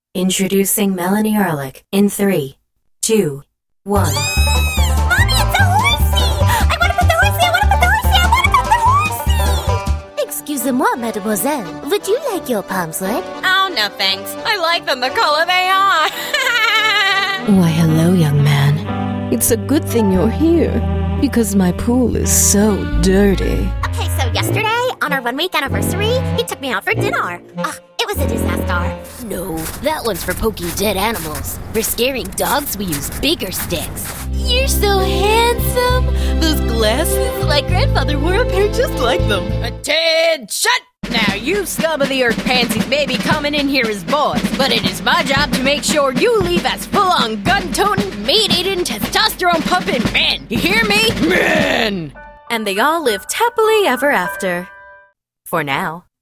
Youthful, energetic, fun!
Kein Dialekt
Sprechprobe: Sonstiges (Muttersprache):